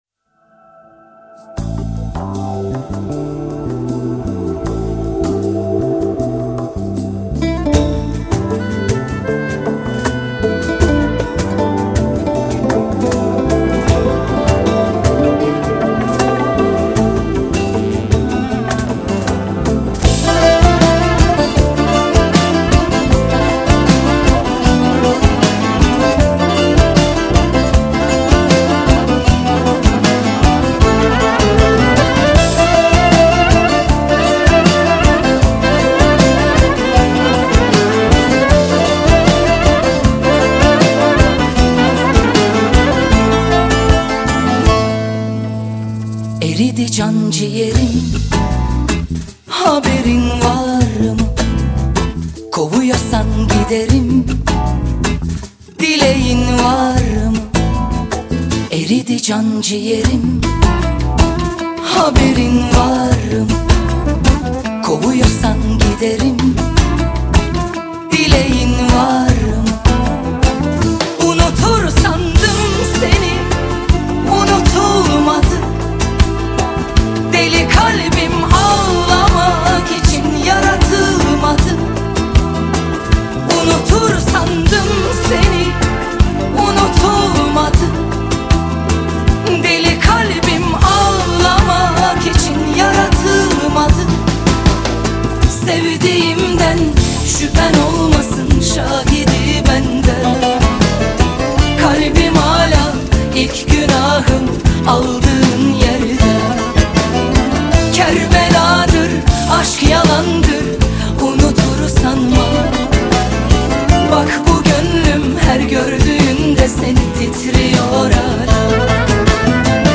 турецкий певец